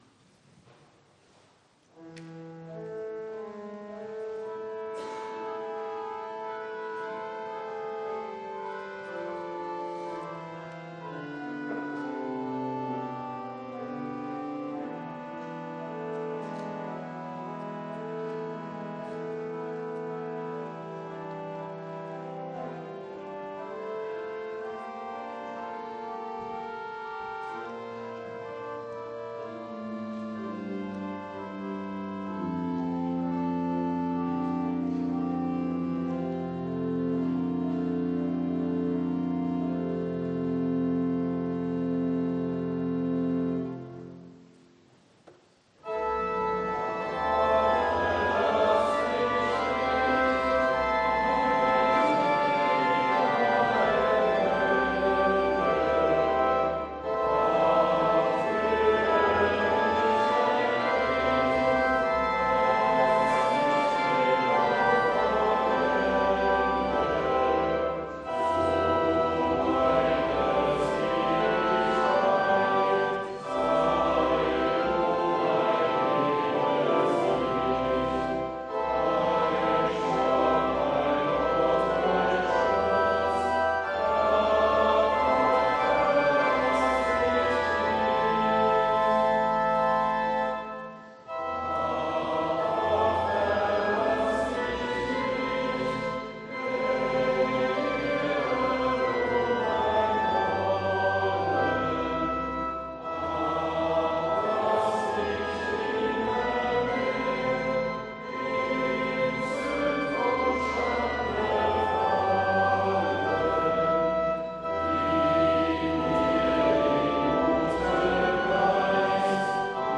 Audiomitschnitt unseres Gottesdienstes vom Sonntag Miserikordias Domini 2022.